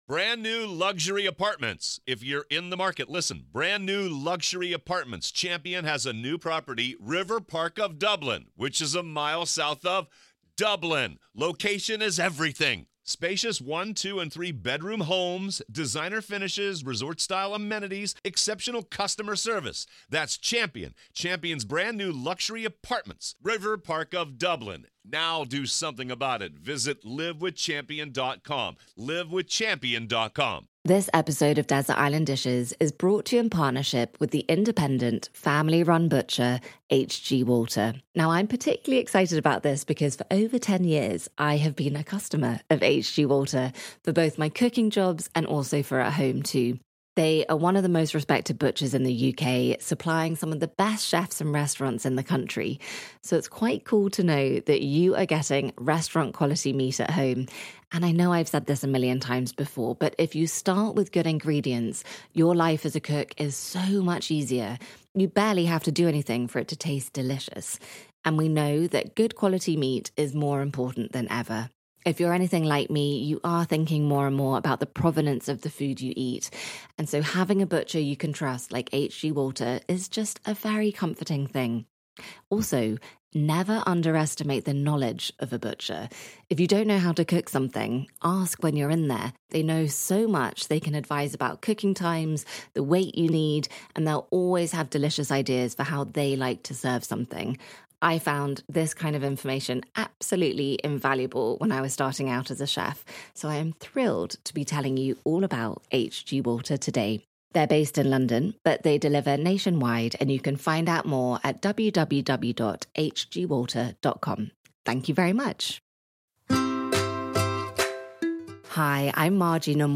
My guest today is Bee Wilson.